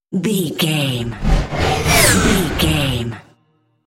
Scifi whoosh pass by
Sound Effects
futuristic
pass by
vehicle